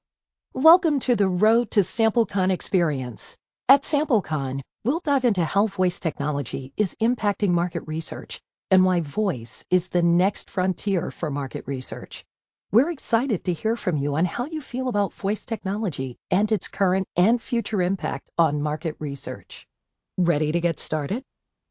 - Voice Ambassadors™ recording the messaging
The entire process from idea to launch took about 4 days including the custom recordings by a professional Voice Ambassador™ and the approval process.
Road_to_samplecon_welcom_2.mp3